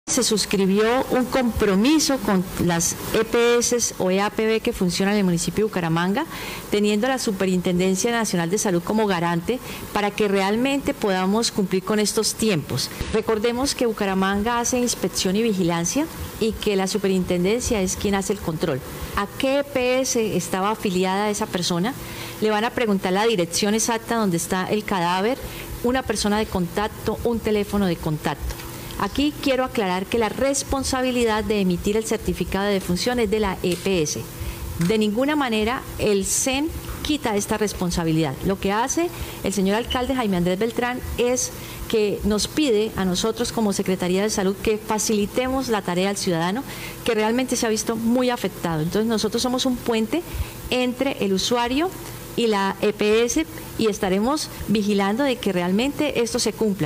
Claudia Amaya, Secretaria de Salud de Bucaramanga